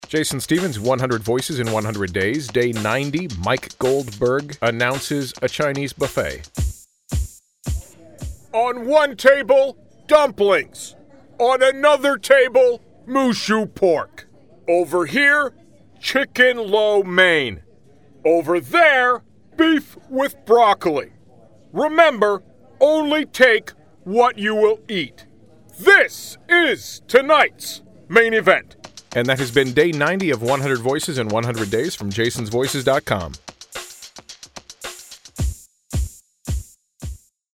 Day 90: UFC commentator Mike Goldberg announces a Chinese buffet.
Tags: celebrity voice match, Mike Goldberg impression, voice over